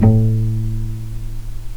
healing-soundscapes/Sound Banks/HSS_OP_Pack/Strings/cello/pizz/vc_pz-A2-pp.AIF at 48f255e0b41e8171d9280be2389d1ef0a439d660
vc_pz-A2-pp.AIF